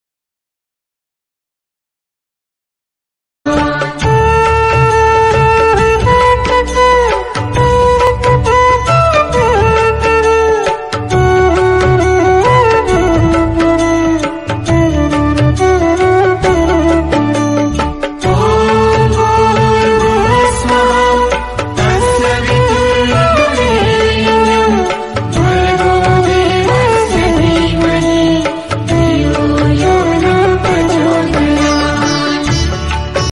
Categories Devotional Ringtones